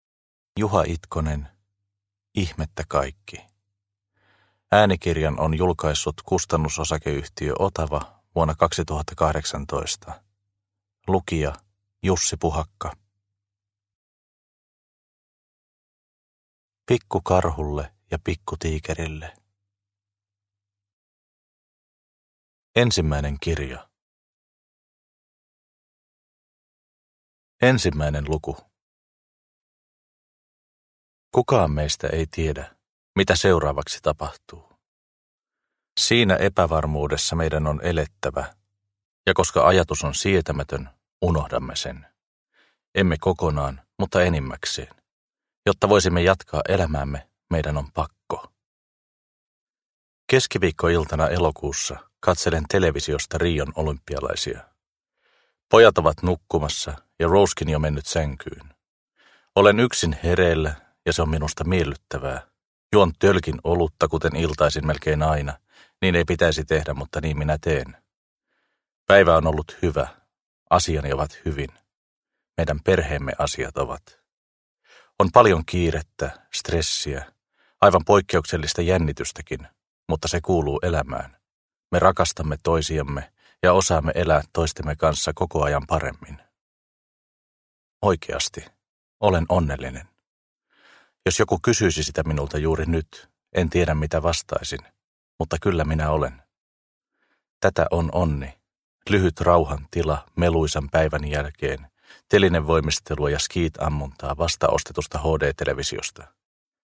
Ihmettä kaikki – Ljudbok – Laddas ner